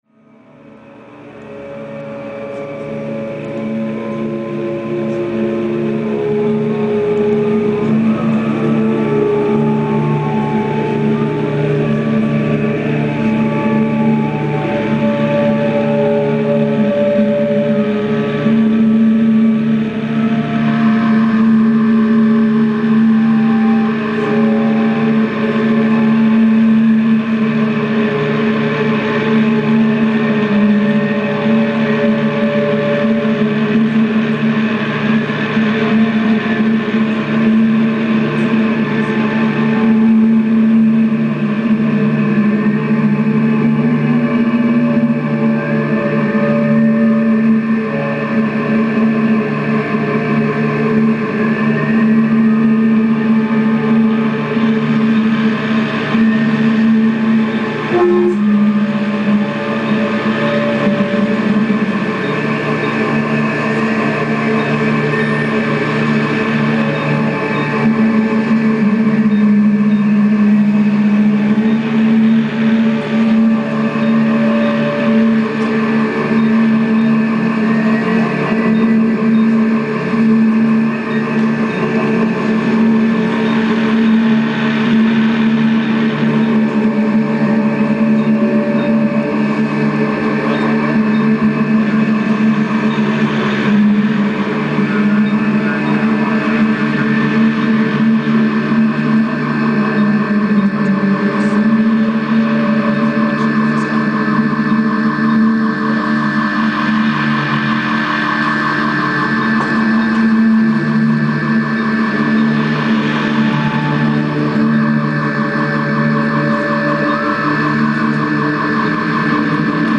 dark ambient and unconventional music
2006 Acadia Cafe